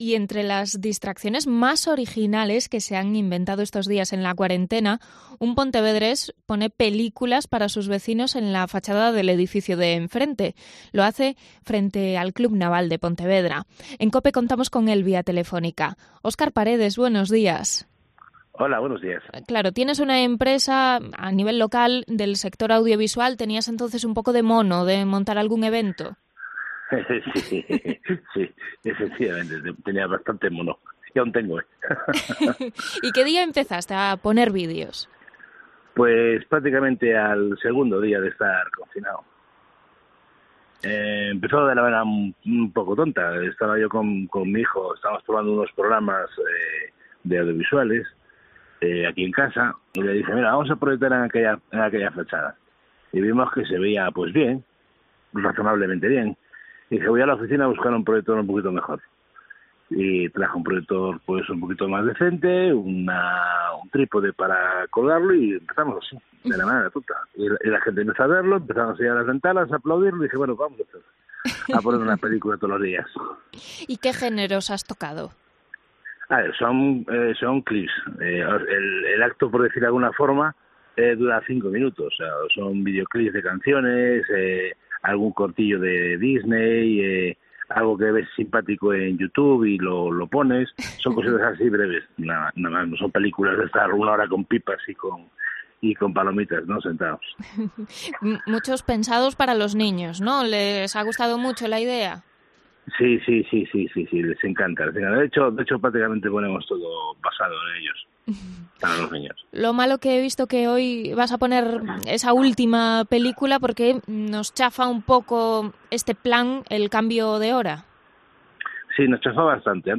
Entrevista al proyector de vídeos en una fachada de Pontevedra